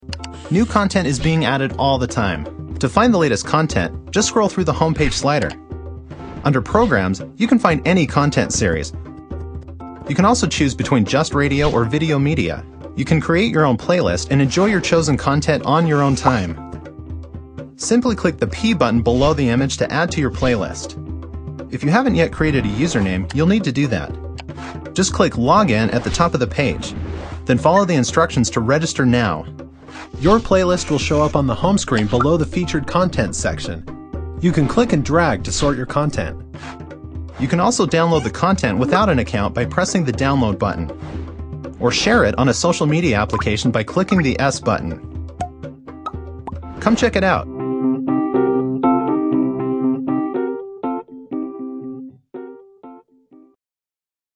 VOICE ACTOR DEMOS
Words that describe my voice are conversational, natural, real.